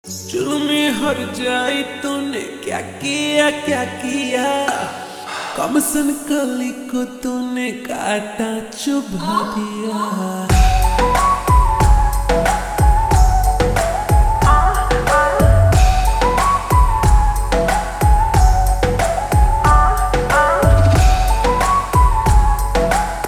(Slowed + Reverb)
romantic ballads